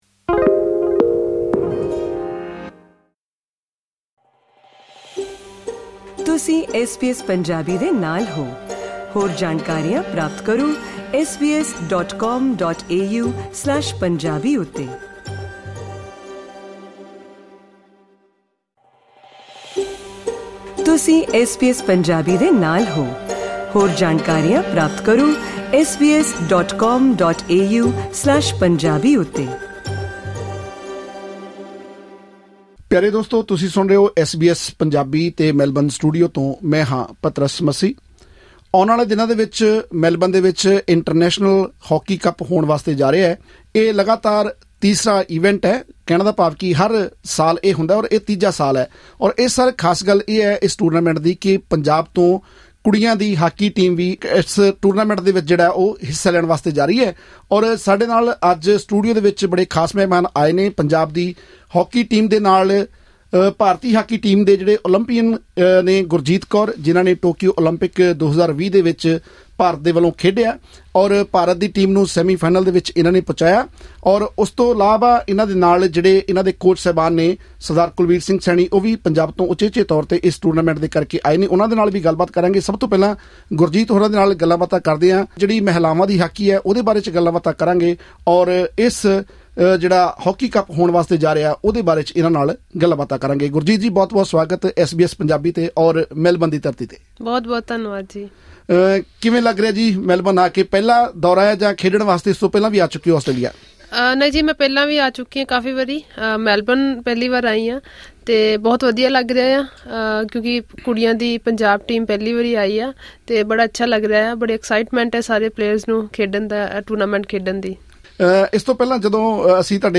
ਖਾਸ ਗੱਲਬਾਤ